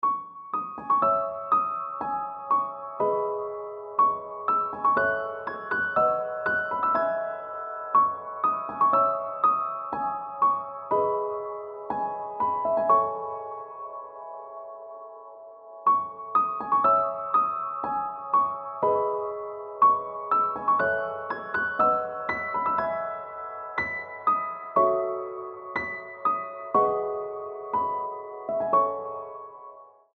• Качество: 320, Stereo
спокойные
без слов
красивая мелодия
пианино
нежные
Красивая расслабляющая фоновая музыка